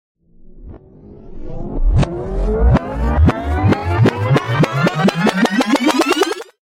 テープの巻き戻し音は、そんな夢を叶えてくれる効果音です。